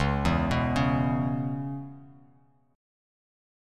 C#m7 Chord